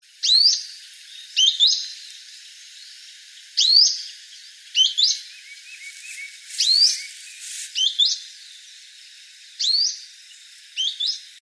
Grey-bellied Spinetail (Synallaxis cinerascens)
Life Stage: Adult
Location or protected area: Reserva Privada San Sebastián de la Selva
Condition: Wild
Certainty: Photographed, Recorded vocal